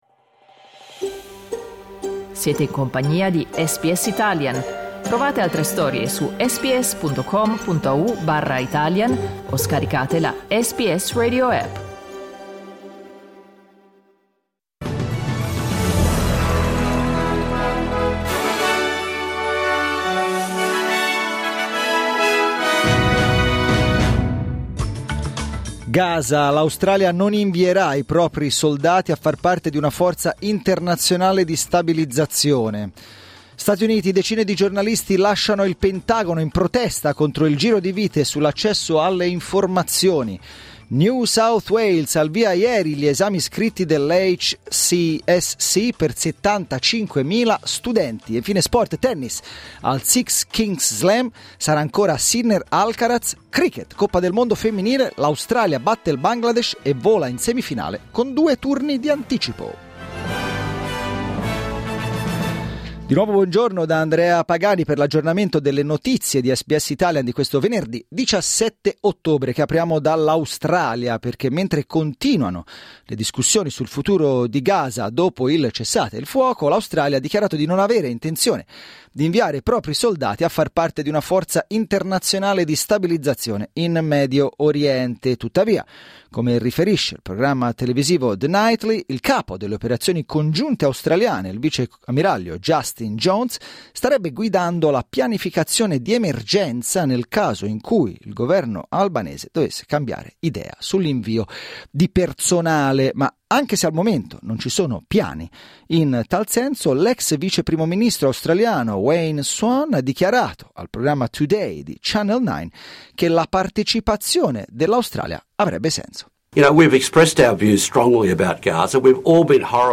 Giornale radio venerdì 17 ottobre 2025
Il notiziario di SBS in italiano.